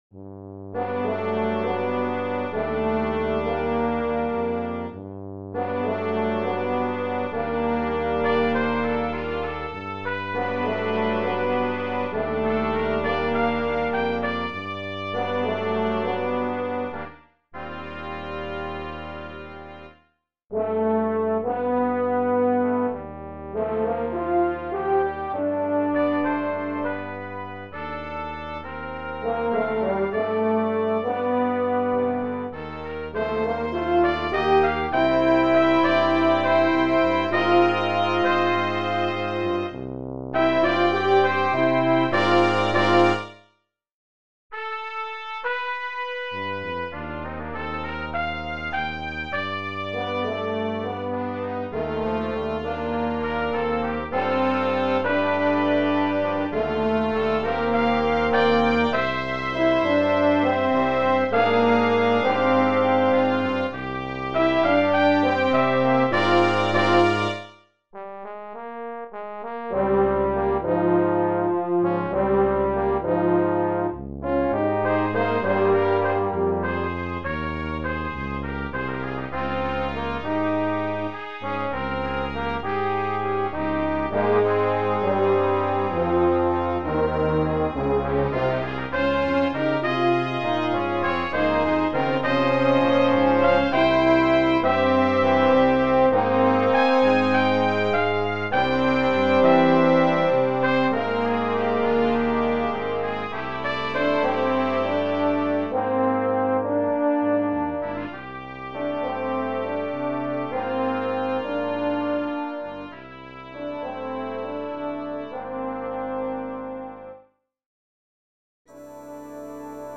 Brass Quintet
Listen to a synthesized sound clip of mvt.2.